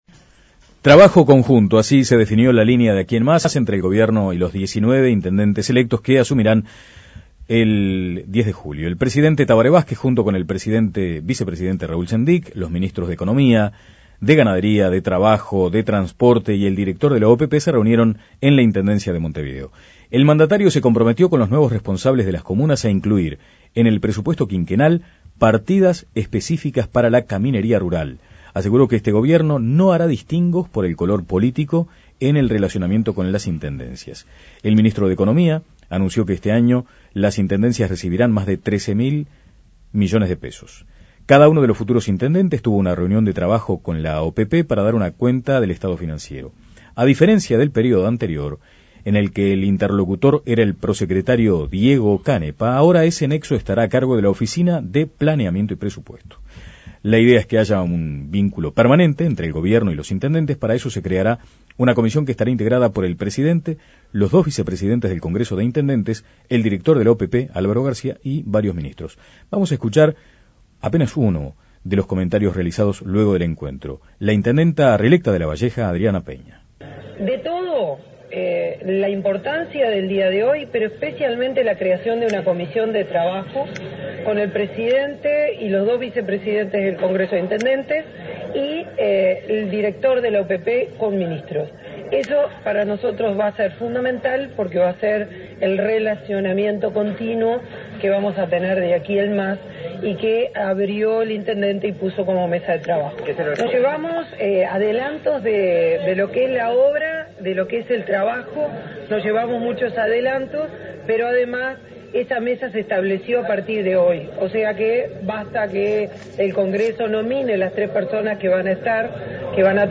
En la tertulia de los jueves